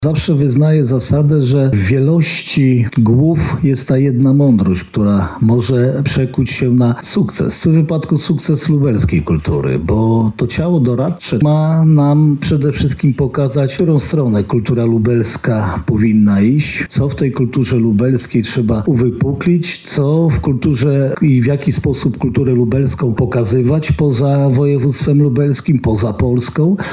Bardzo się cieszę, że powstaje Rada Kultury Województwa Lubelskiego– podkreślał podczas uroczystości marszałek Jarosław Stawiarski